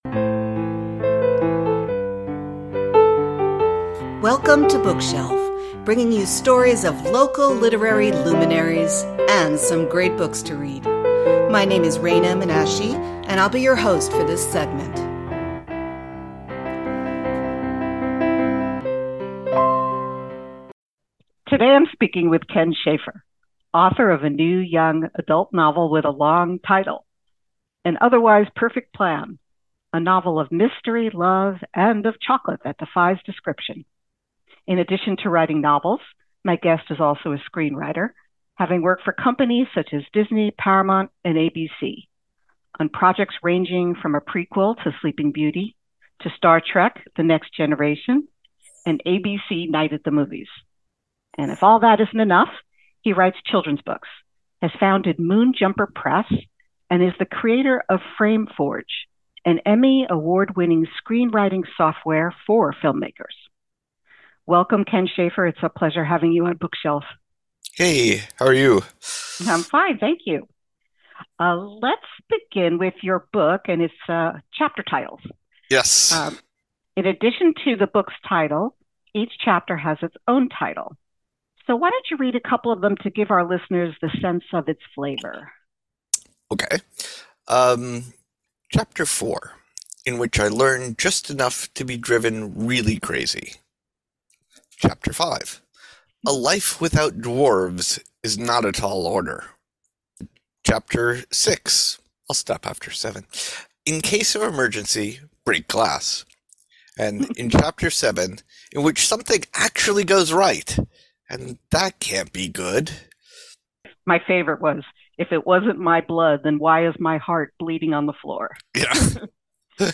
Hear full interview aired on KNSJ Radio